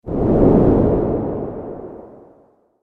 دانلود آهنگ باد 60 از افکت صوتی طبیعت و محیط
دانلود صدای باد 60 از ساعد نیوز با لینک مستقیم و کیفیت بالا
جلوه های صوتی